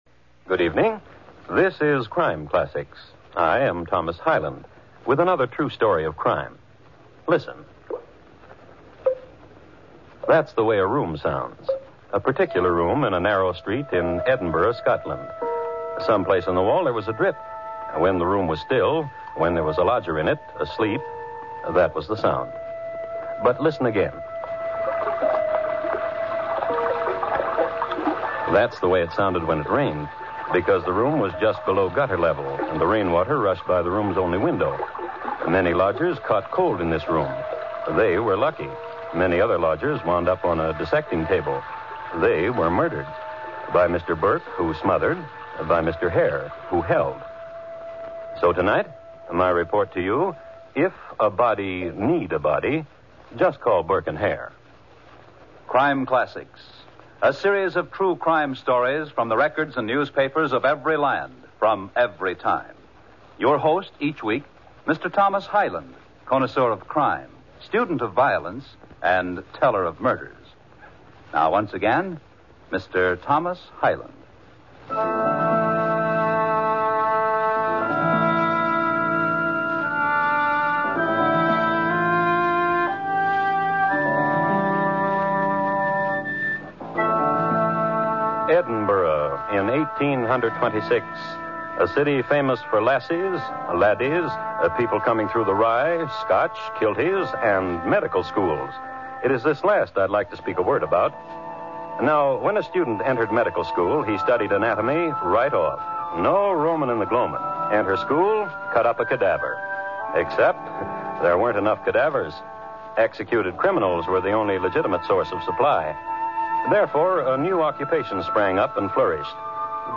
Crime Classics Radio Program